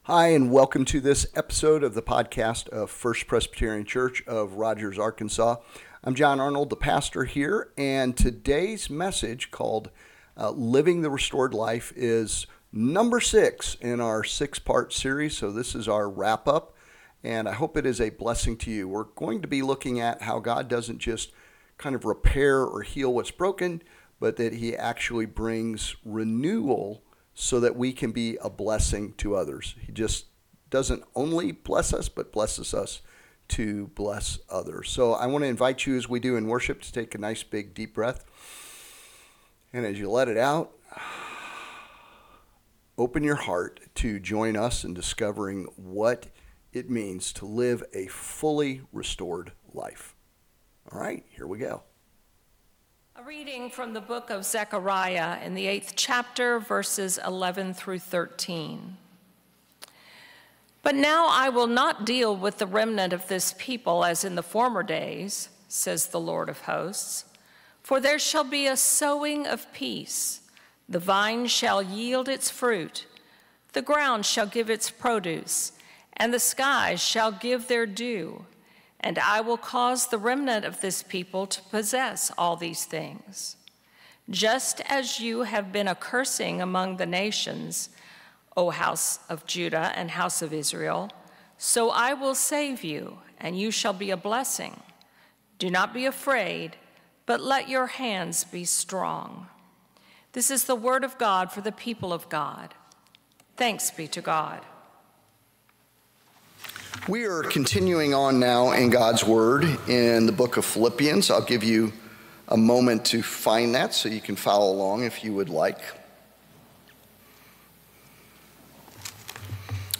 Discover how God restores us not just for ourselves but to bless others in 'Living the Restored Life'—a sermon message of gratitude and renewal.